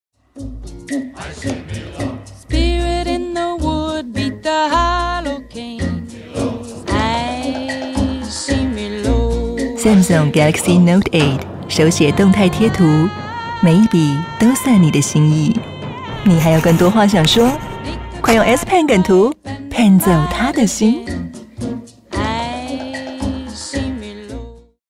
國語配音 女性配音員
她在廣告中憑藉真摯而又帶有親和力的聲音，成功塑造出鮮明的品牌形象，是業界廣告配音的熱門選擇。